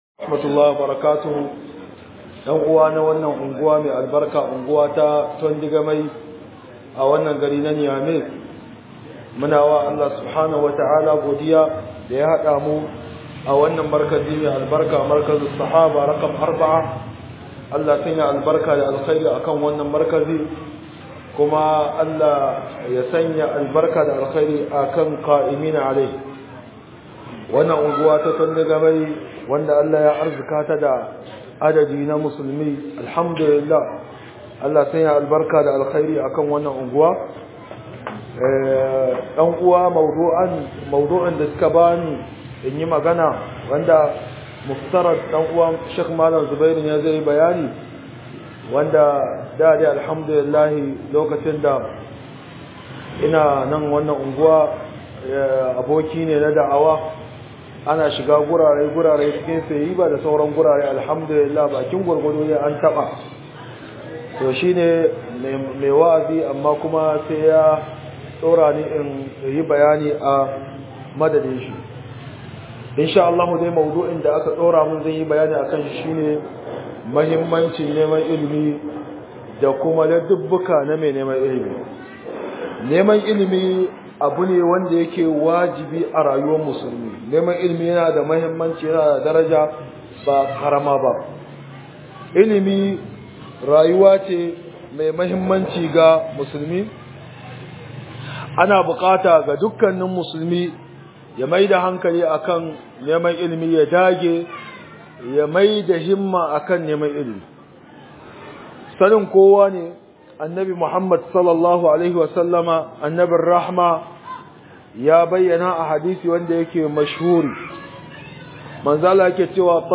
Muhadara Markaz Sahaba